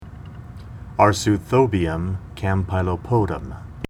Pronunciation Cal Photos images Google images